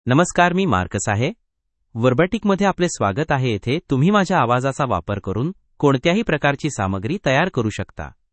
Marcus — Male Marathi AI voice
Voice sample
Listen to Marcus's male Marathi voice.
Male
Marcus delivers clear pronunciation with authentic India Marathi intonation, making your content sound professionally produced.